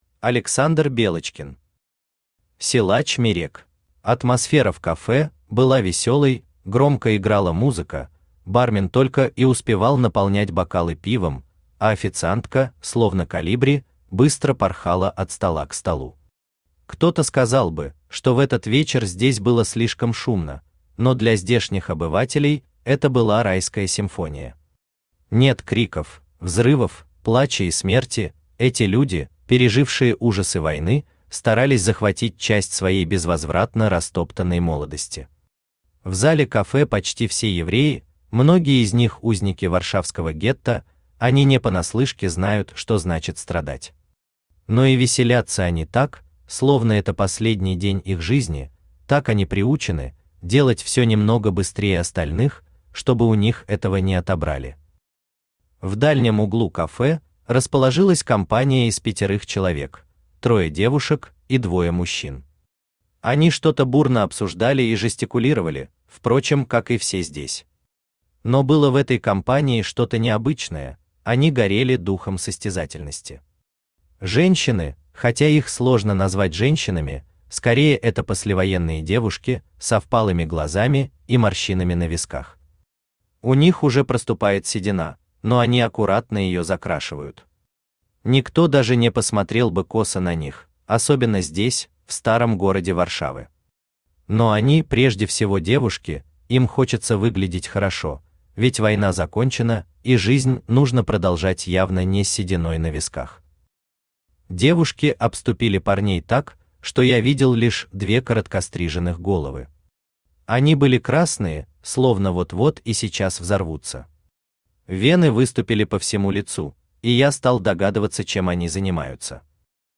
Аудиокнига Силач Мирек | Библиотека аудиокниг
Aудиокнига Силач Мирек Автор Александр Белочкин Читает аудиокнигу Авточтец ЛитРес.